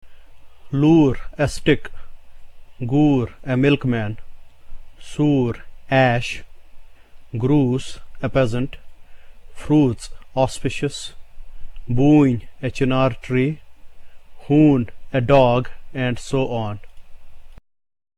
The preceding vowel now occurs with a macron, implying, as before, that the new sound that we have, is an extension of the basic sound U. This symbol has the same sound as the combination "OO" in the English word MOON.
Here are some of the words using this sound in Kashmiri: